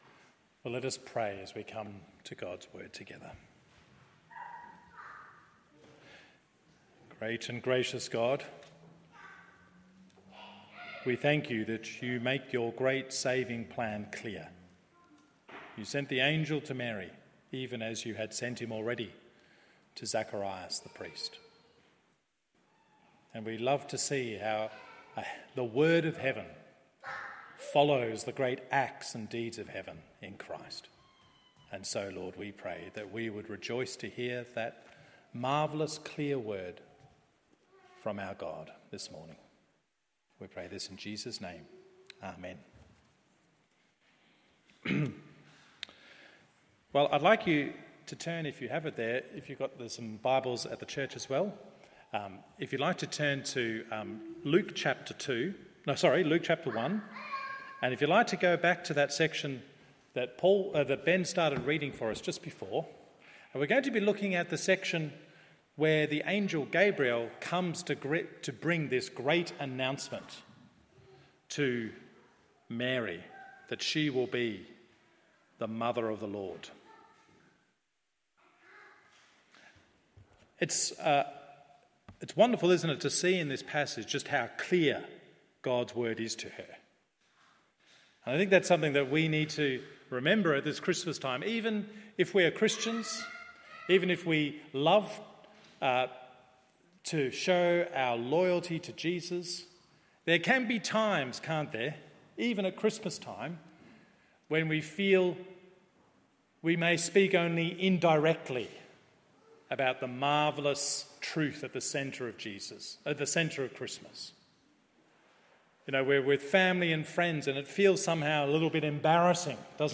CHRISTMAS SERVICE Luke 1:26-38…